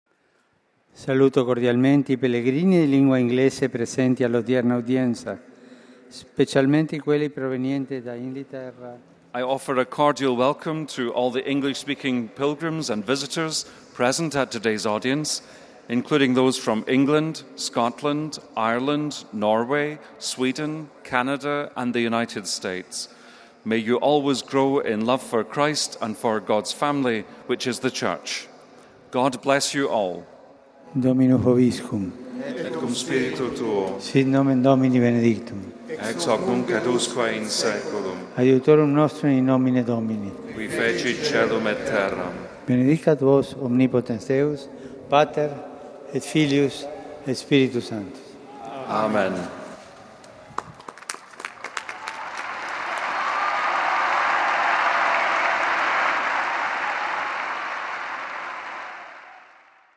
29 May, 2013 - Pope Francis held his weekly general audience in the open in St. Peter’s Square in the Vatican on Wednesday.
He concluded Wednesday's general audience with his blessing: